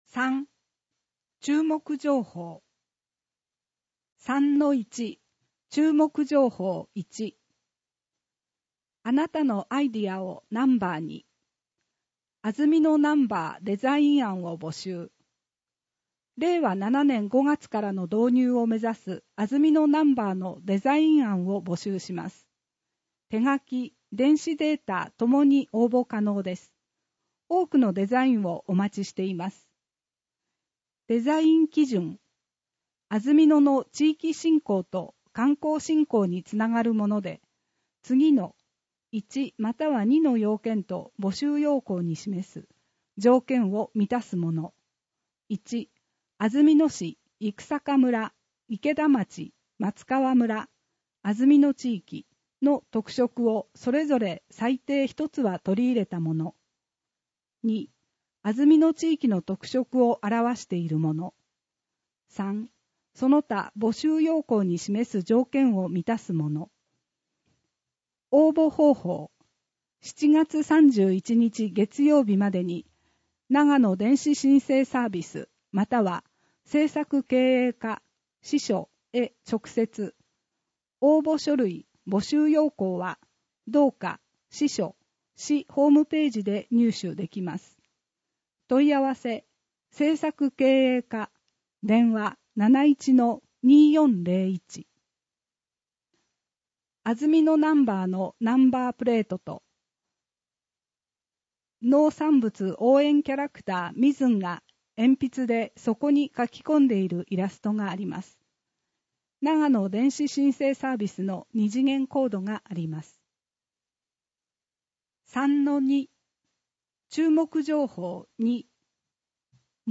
「広報あづみの」を音声でご利用いただけます。